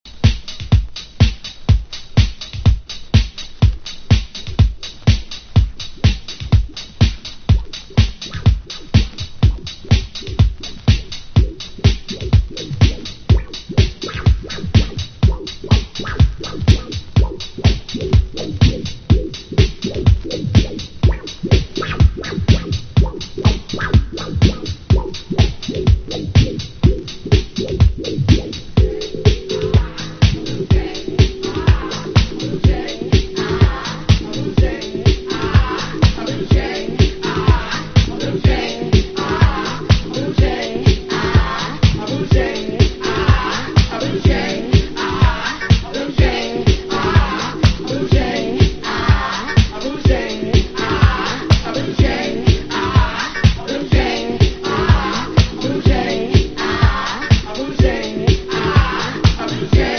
MAIN VOCAL